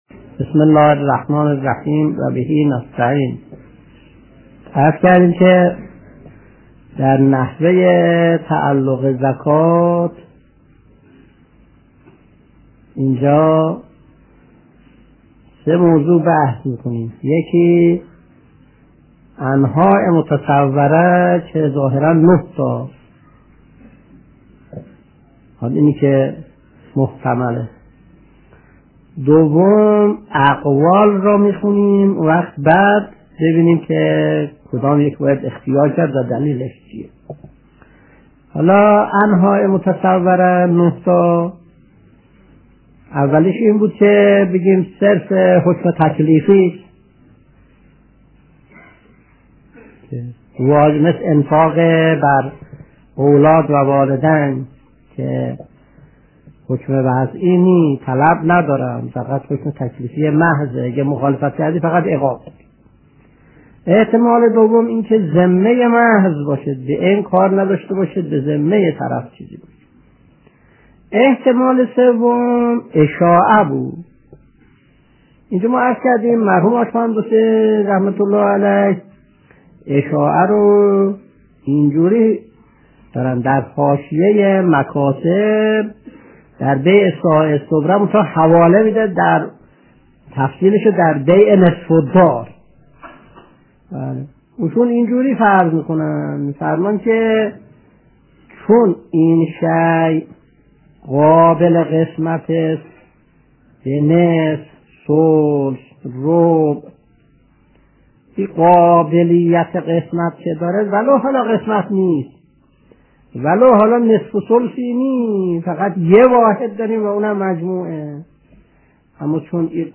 درس 239 : (21/10/1362)